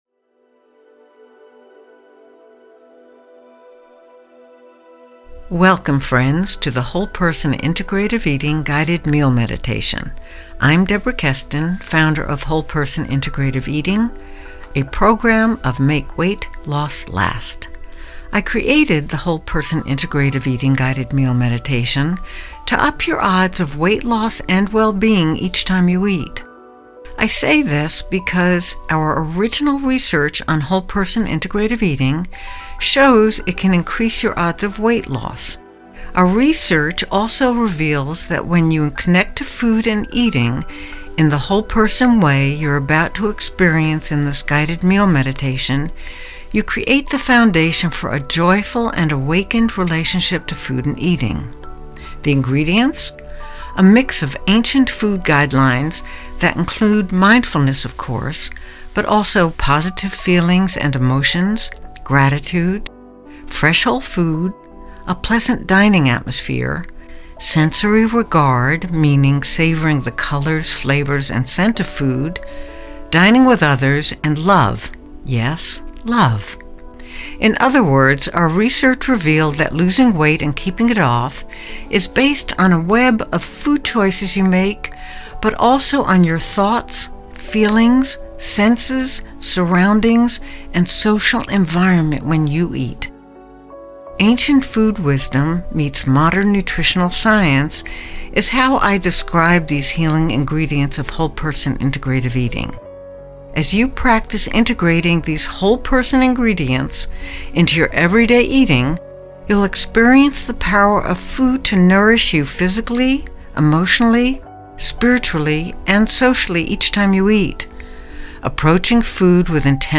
WHOLE PERSON INTEGRATIVE EATING (WPIE) GUIDED MEAL MEDITATION
The AUDIO version that follows will lead you through the meditation in more detail.
Meal-Meditation.mp3